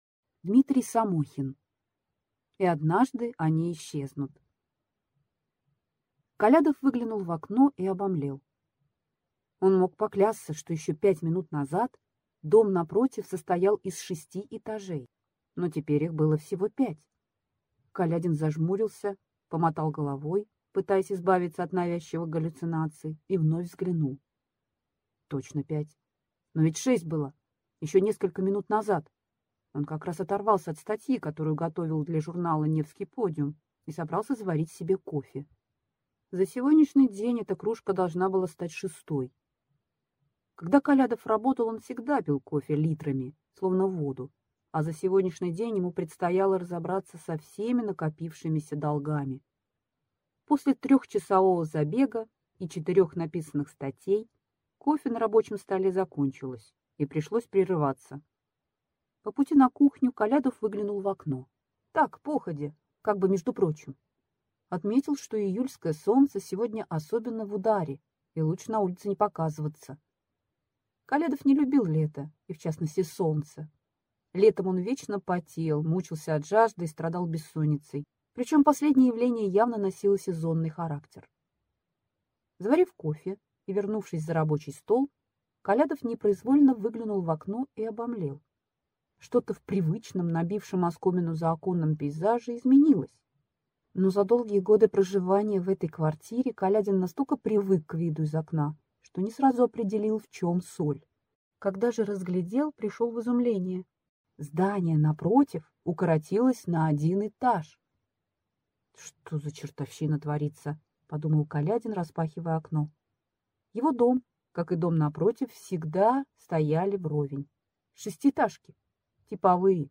Аудиокнига И однажды они исчезнут | Библиотека аудиокниг